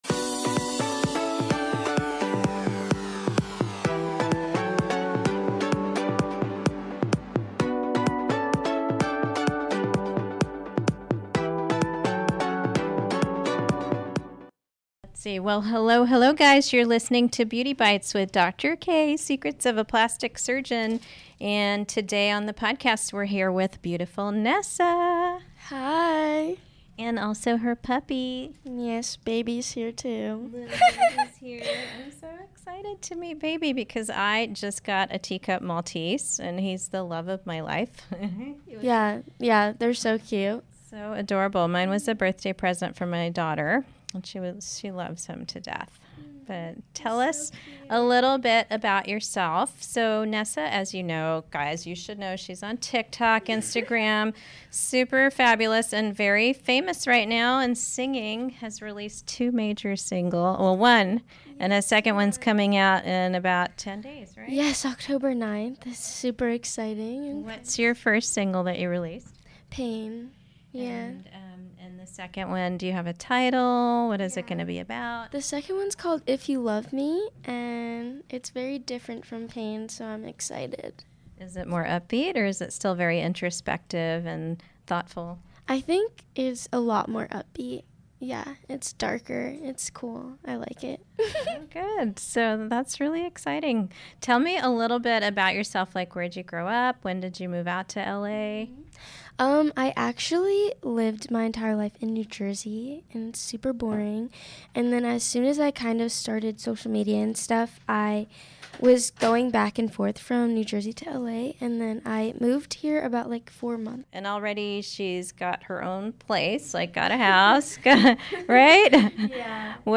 I am excited to be joined with Tik Tok Star, Nessa Barrett.